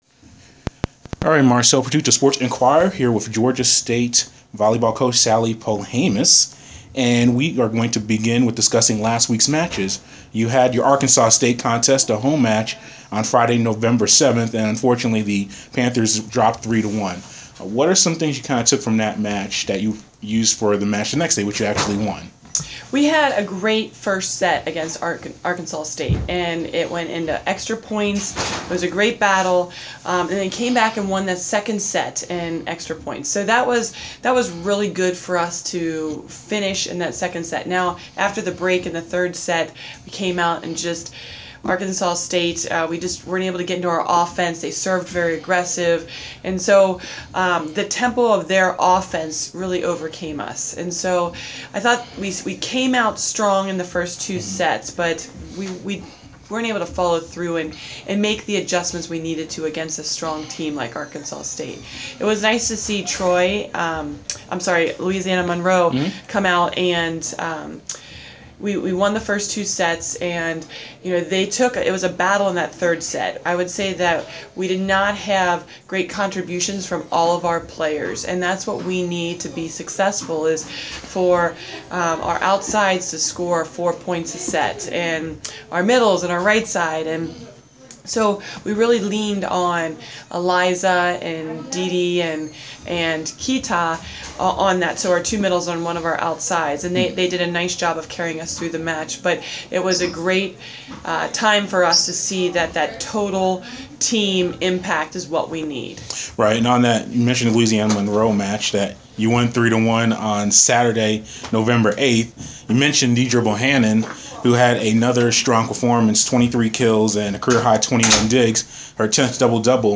Inside the Inquirer: Interview